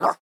DogBark.wav